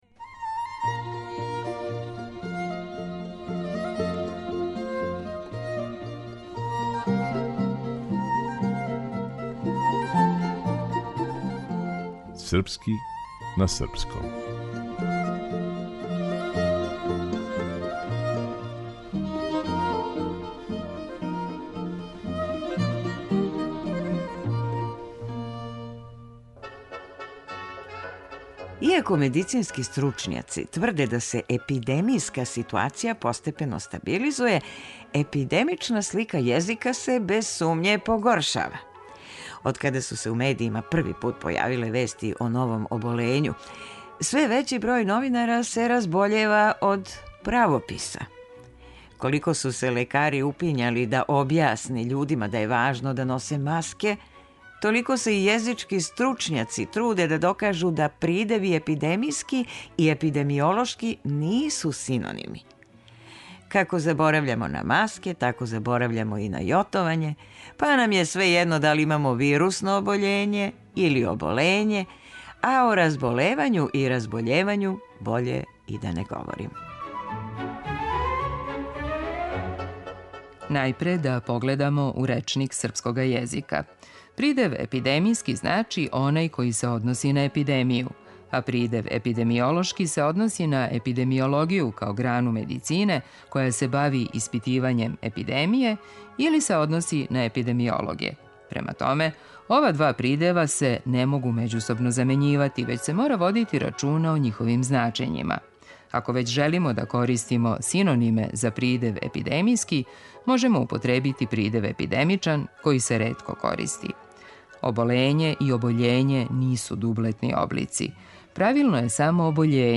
Глумица